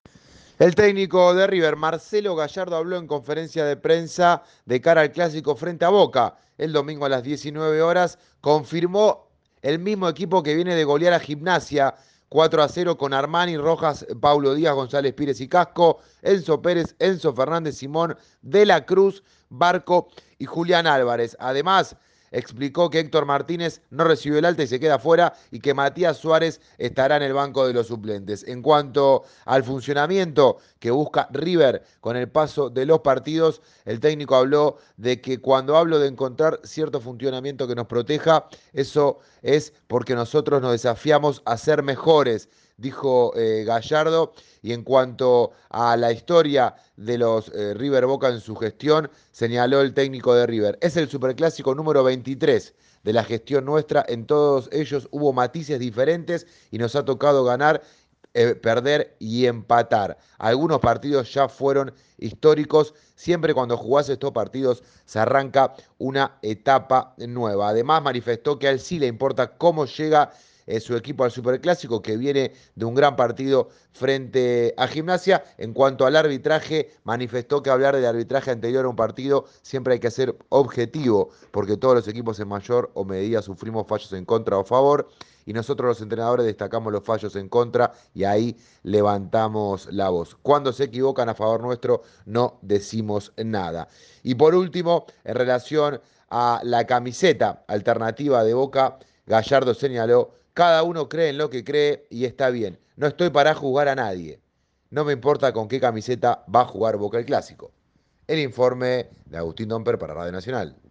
Marcelo Gallardo arrancó la conferencia confirmando el equipo de para el Superclásico.